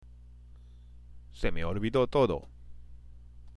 ＜発音と日本語＞
（セ　メ　オルビド　トド）